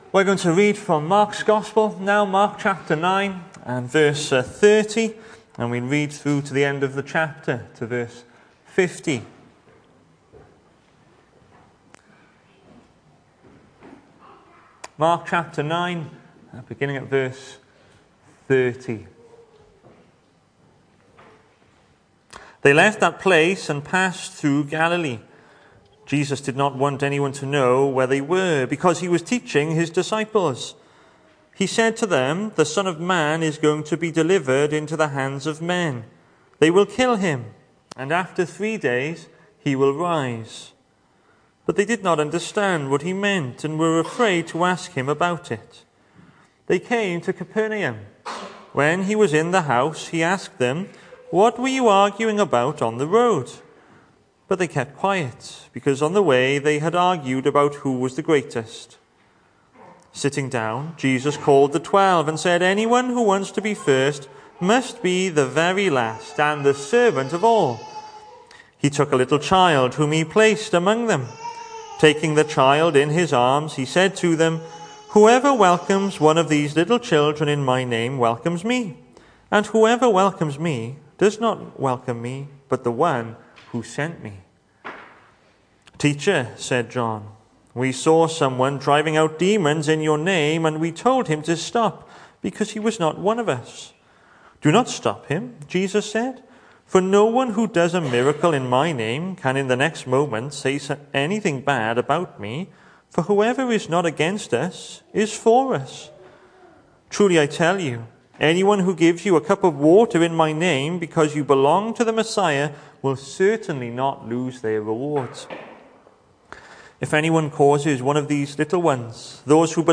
The 16th of February saw us host our Sunday morning service from the church building, with a livestream available via Facebook.